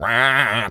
duck_quack_hurt_08.wav